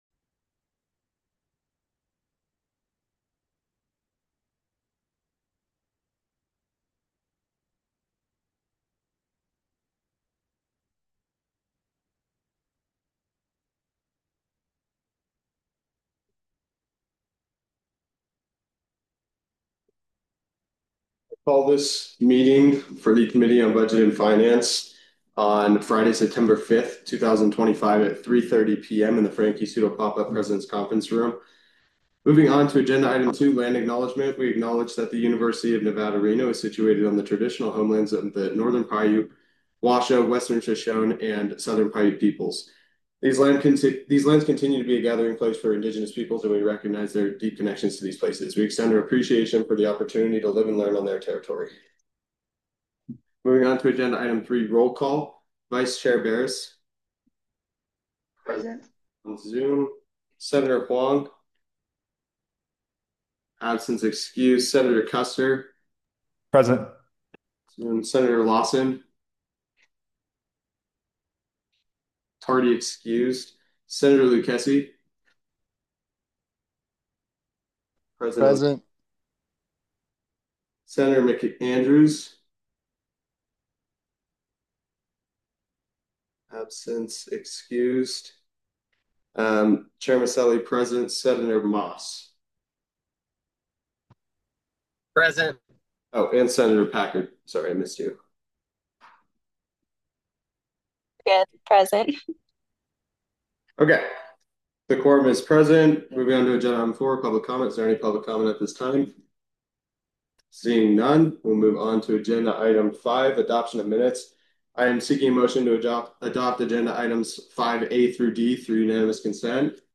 Meeting Type : Budget and Finance Committee
Audio Minutes